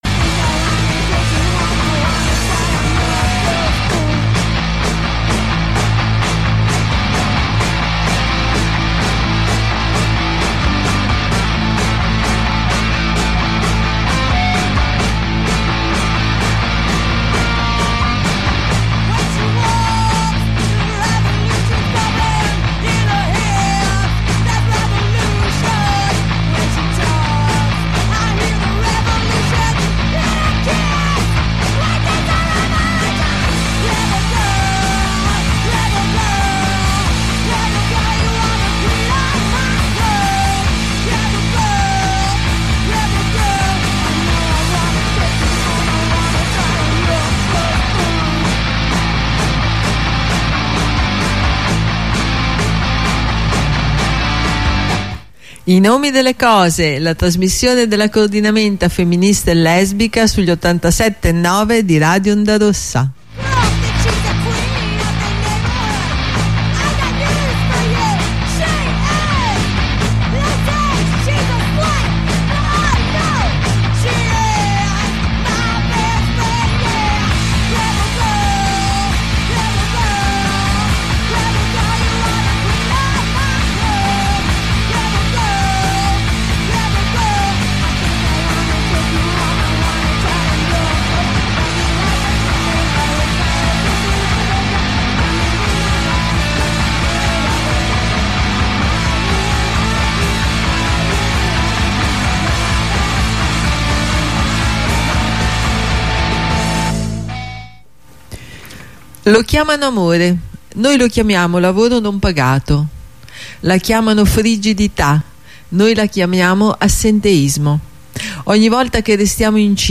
primo collegamento introduttivo al corteo